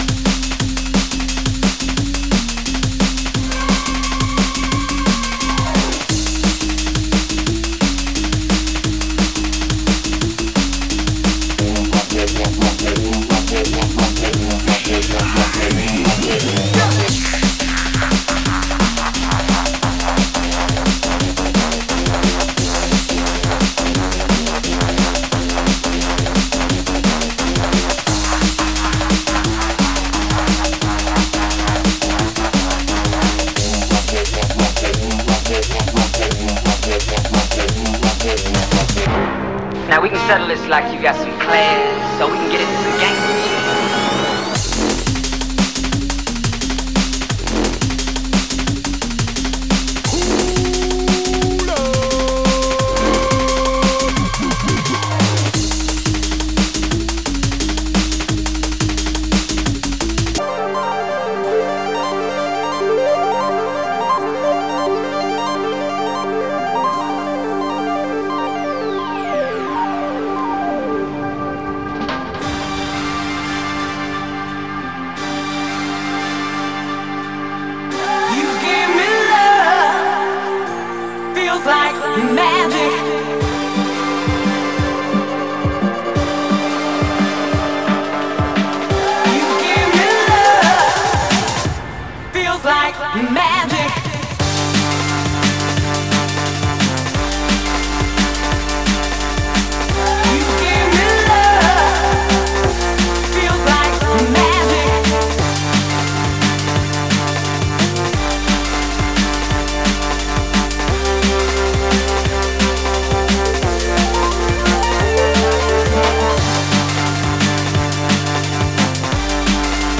Drum N Bass Tags